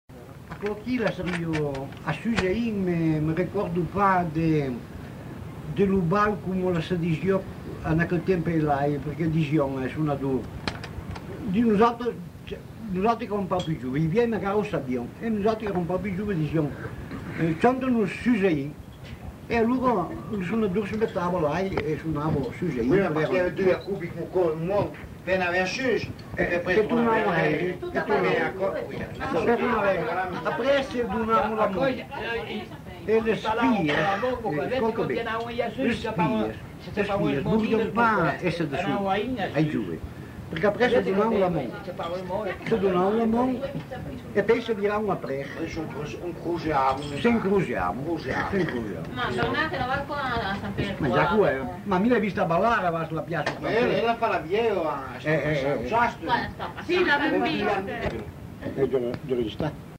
Aire culturelle : Val Varaita
Lieu : Bellino
Genre : témoignage thématique